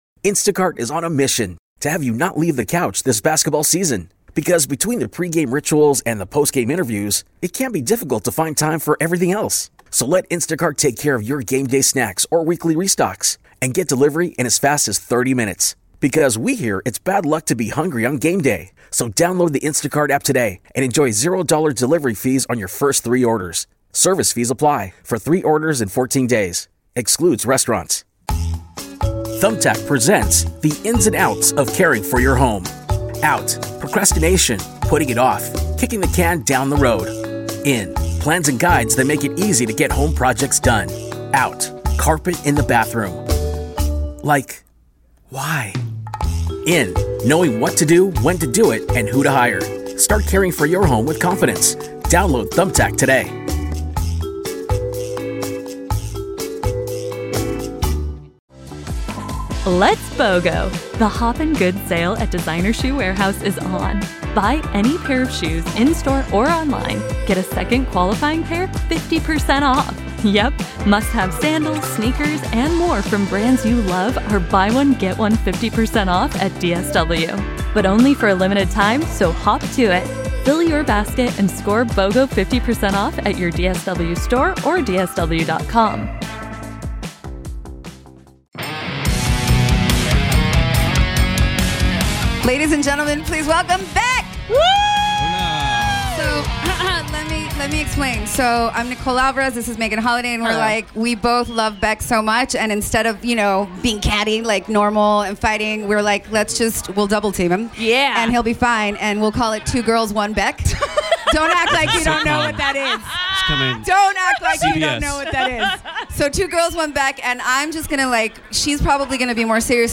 Beck at Almost Acoustic Christmas 2024
Interview